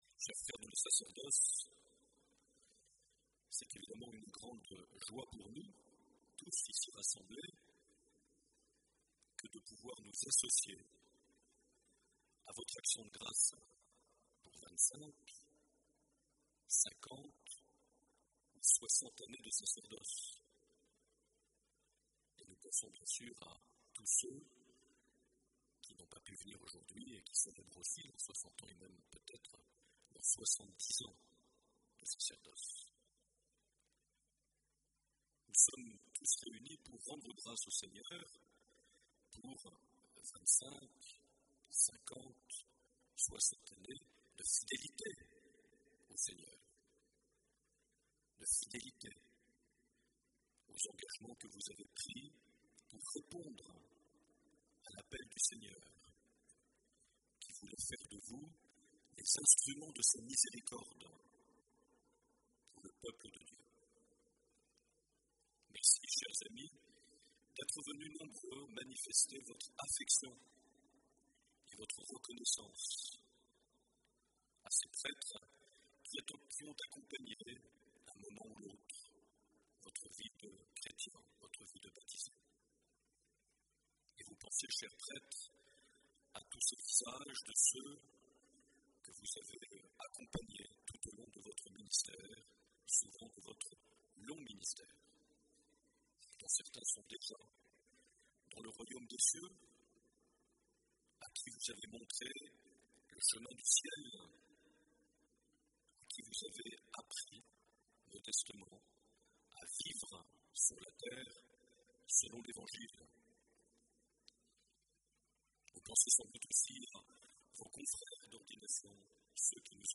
29 juin 2012 - Cathédrale de Bayonne - Messe d'action de grâces avec les prêtres jubilaires
Accueil \ Emissions \ Vie de l’Eglise \ Evêque \ Les Homélies \ 29 juin 2012 - Cathédrale de Bayonne - Messe d’action de grâces avec les (...)
Une émission présentée par Monseigneur Marc Aillet